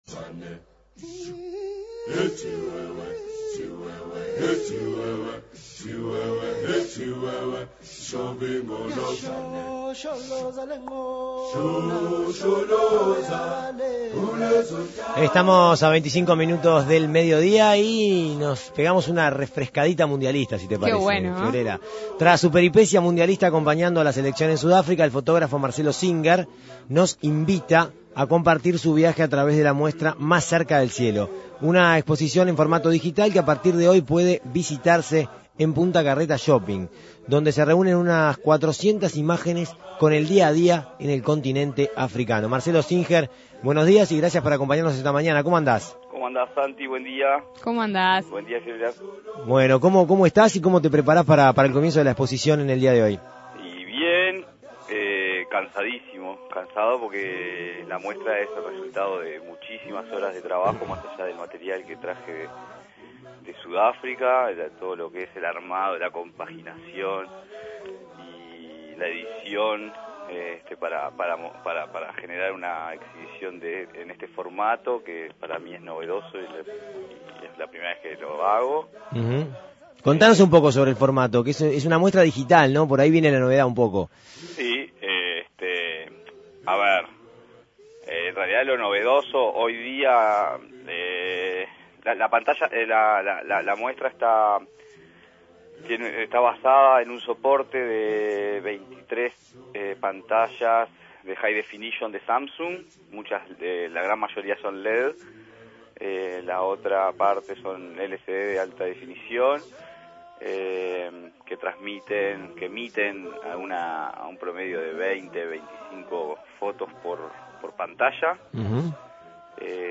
dialogó en la Segunda Mañana de En Perspectiva.